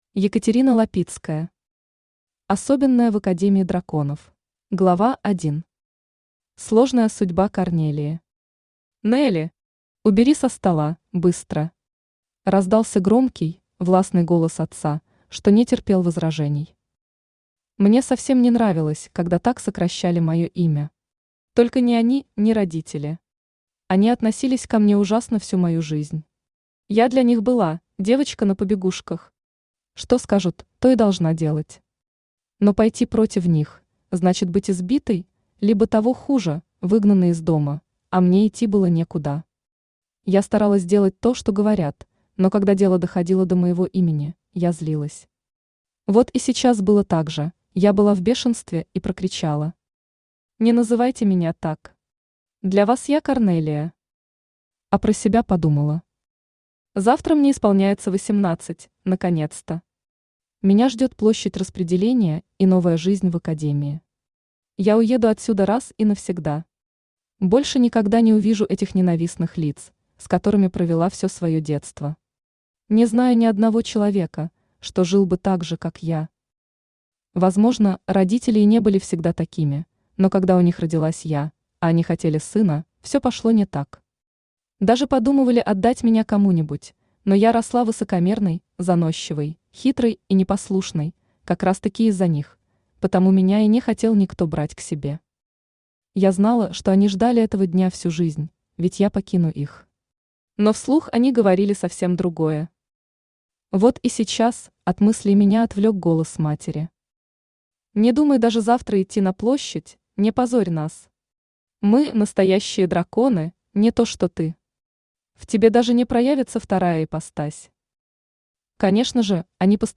Аудиокнига Особенная в академии драконов | Библиотека аудиокниг
Aудиокнига Особенная в академии драконов Автор Екатерина Сергеевна Лапицкая Читает аудиокнигу Авточтец ЛитРес.